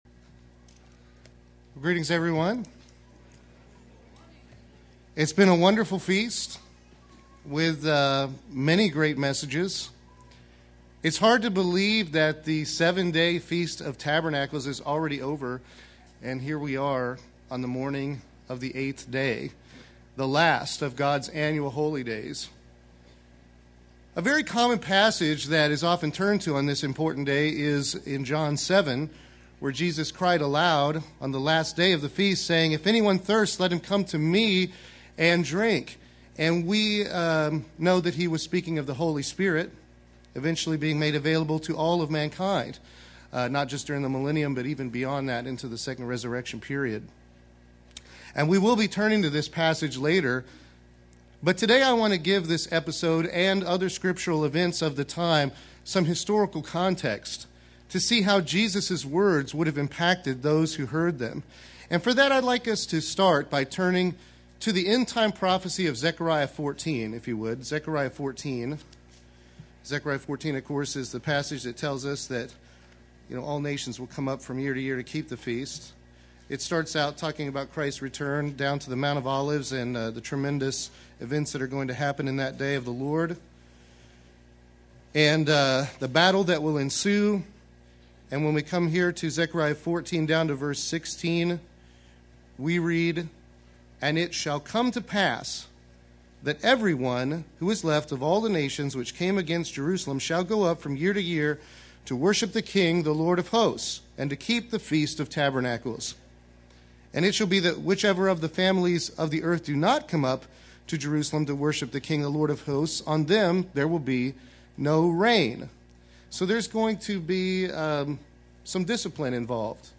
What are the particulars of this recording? This sermon was given at the Branson, Missouri 2011 Feast site.